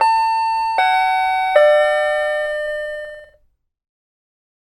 Deurbel elektronische
deurbel-elektronische.mp3